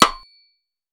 pcp_rim01.wav